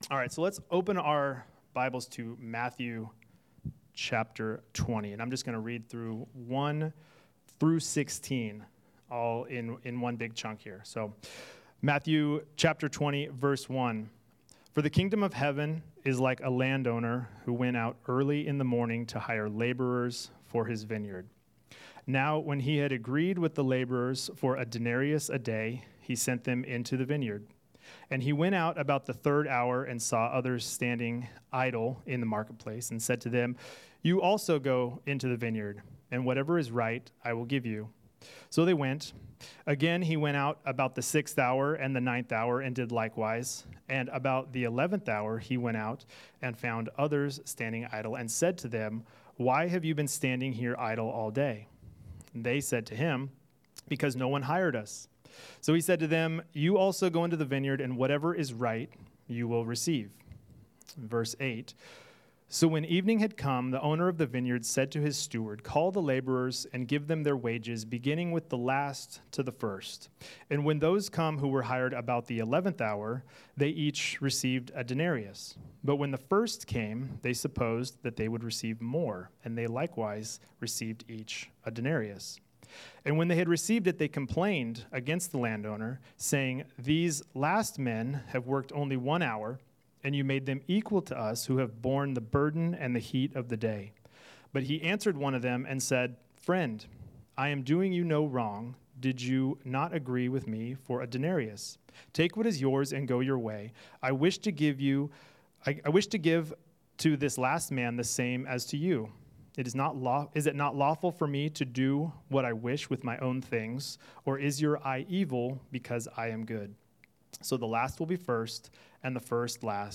Sermon Series – Calvary Chapel West Ashley